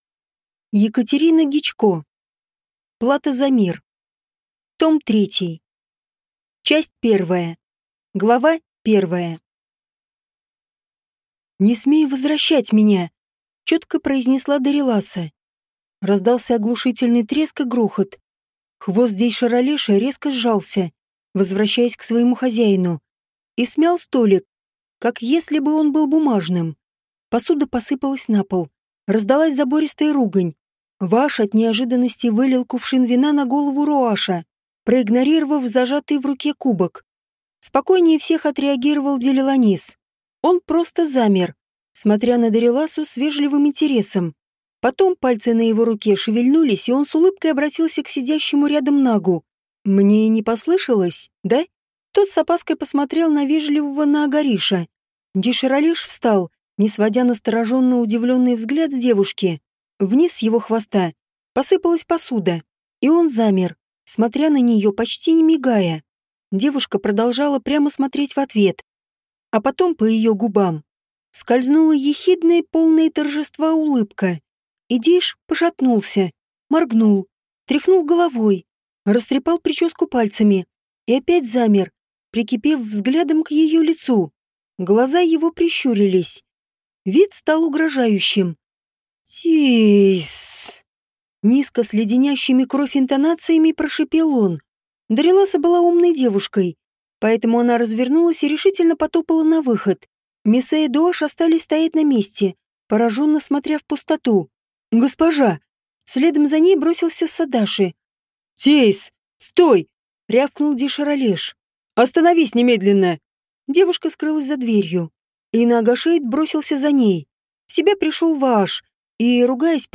Аудиокнига Плата за мир. Том 3 | Библиотека аудиокниг